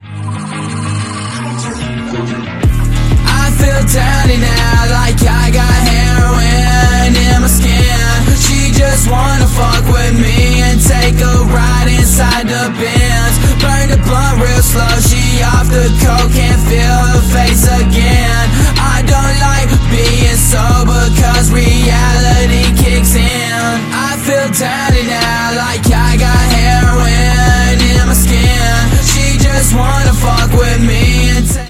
басы
приятный мужской голос
Cloud Rap
Alternative Rap
Басовый рэпчик про запрещенные вещества